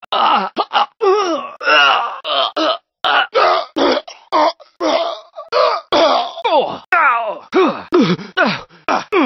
gta-san-andreas-pedestrian-voices-coughing-pain-male-audiotrimmer_44t7VeO.mp3